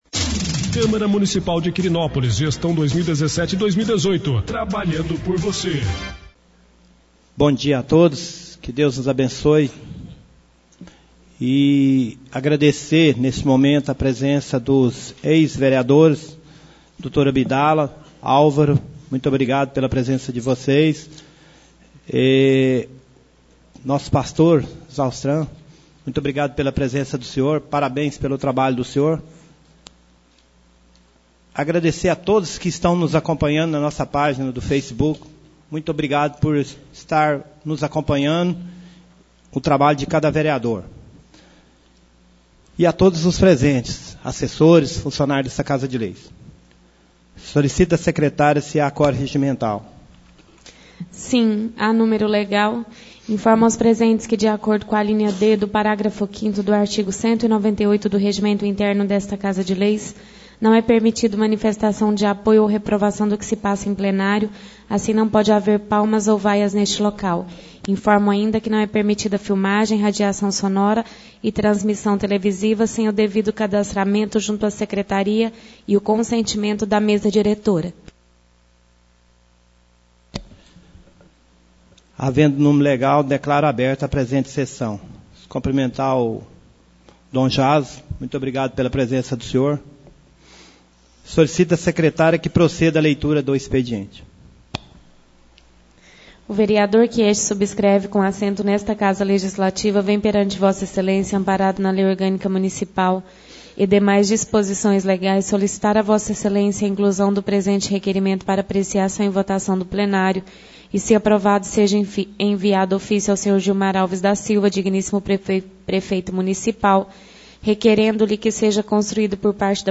3ª Sessão Ordinária do Mês de Setembro 2017